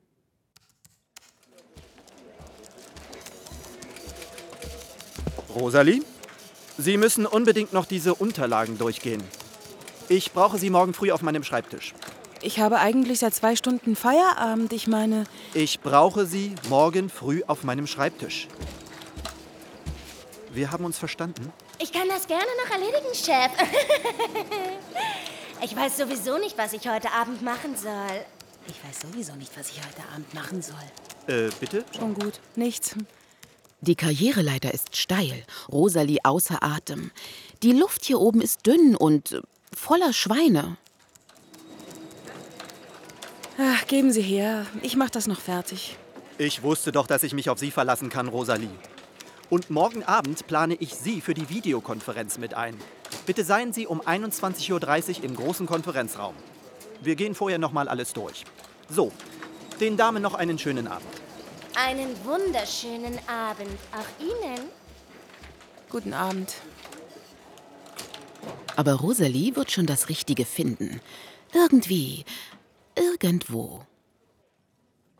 Fortsetzung des Hörspiels über das Glück und die Liebe mit den Synchronstimmen von Julia Roberts und Richard Gere; nach den Büchern von Katja Reider & Jutta Bücker